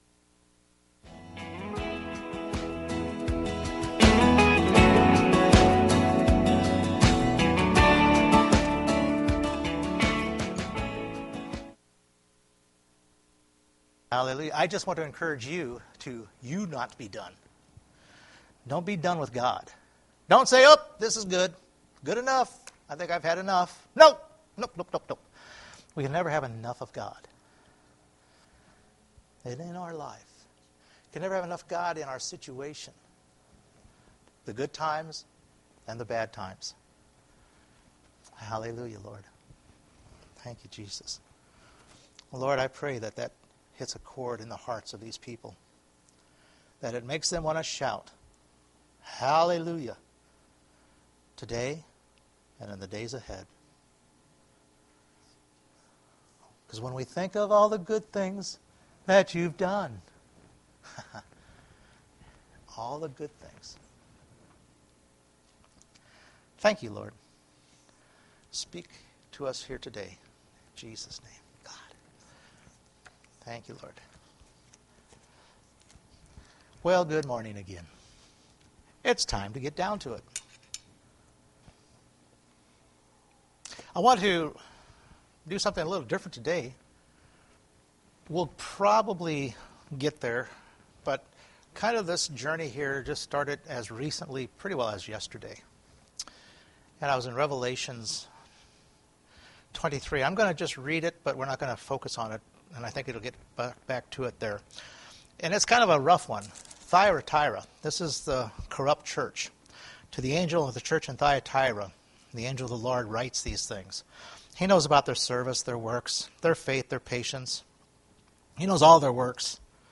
Romans Chapter 6 Service Type: Sunday Morning We can move past the sin from our past